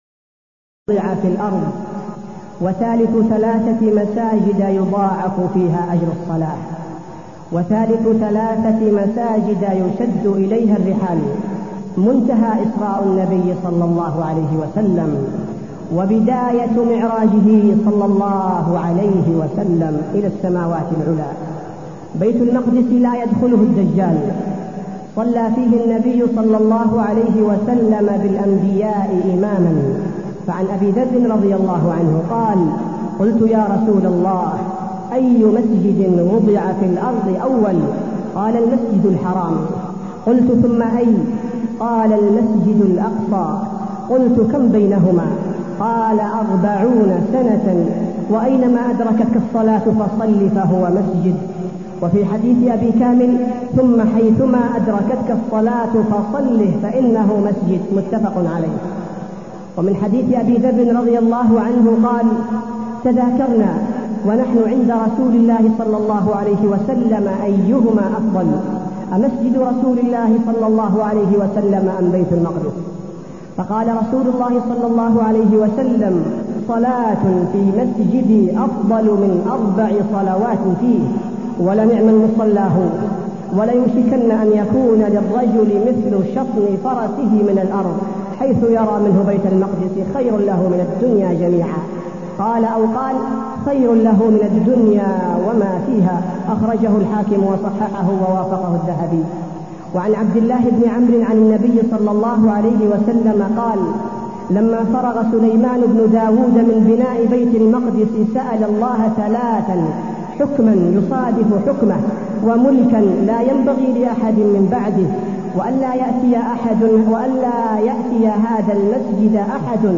تاريخ النشر ١٦ رجب ١٤٢١ هـ المكان: المسجد النبوي الشيخ: فضيلة الشيخ عبدالباري الثبيتي فضيلة الشيخ عبدالباري الثبيتي القدس آلام وجراح